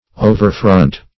Search Result for " overfront" : The Collaborative International Dictionary of English v.0.48: Overfront \O`ver*front"\, v. t. To confront; to oppose; to withstand.